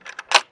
gear_off.wav